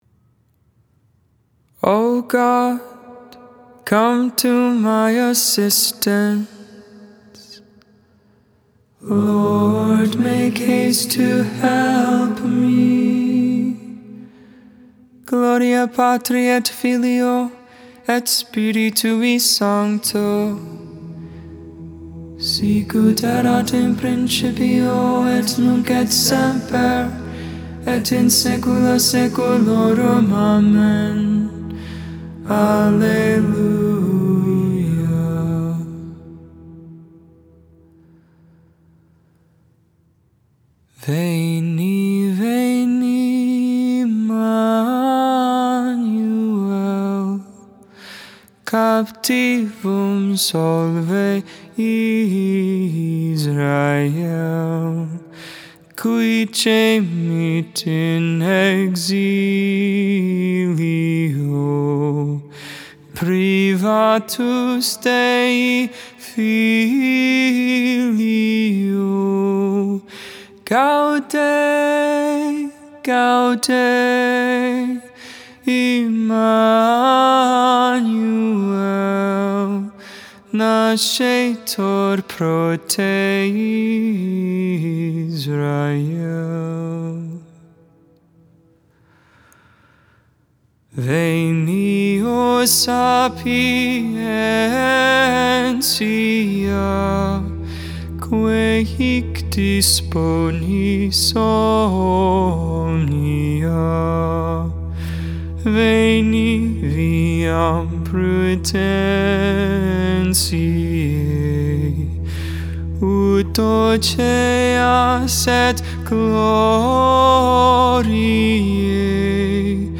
Psalm 145 - II (Gregorian tone 7, maj.)